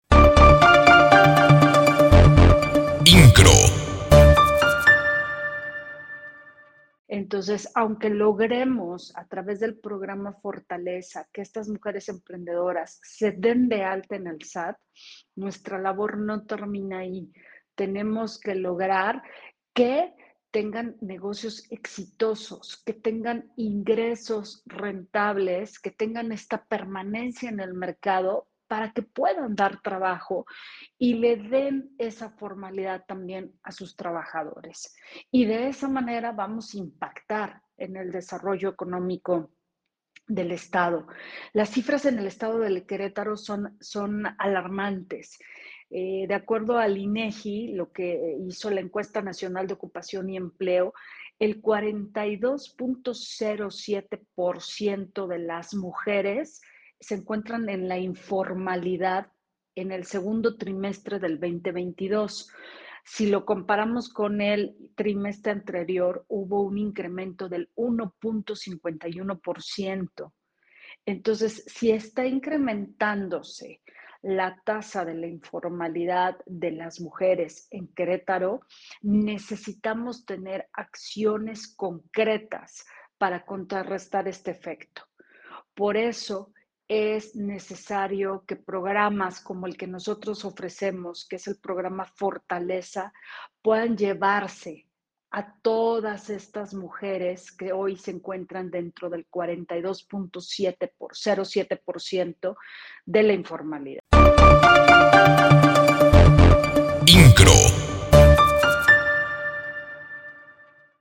en el marco de la graduación de la primera generación del programa “Fortalezza” auspiciado por esta institución
Teniendo como escenario la Casa de la Mujer del Patronato del Sistema Estatal DIF